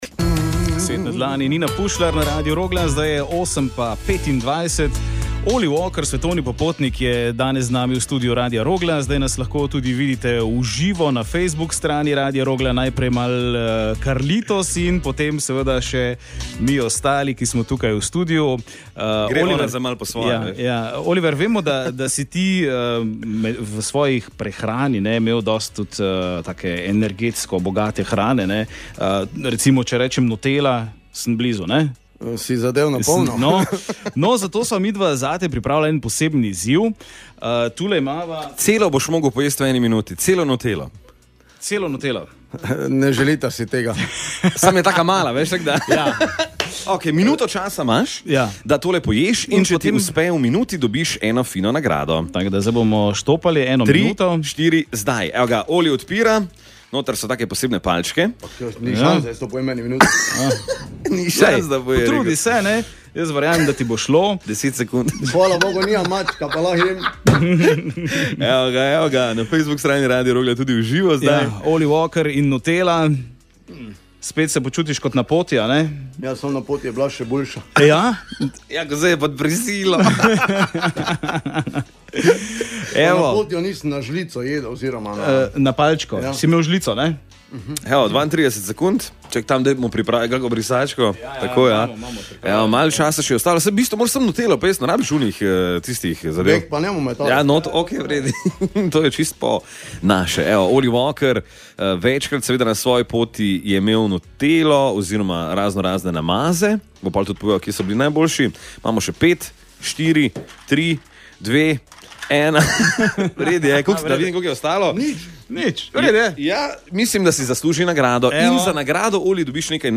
Z neverjetnima popotnikoma smo preživeli ponedeljkovo jutro na Radiu Rogla.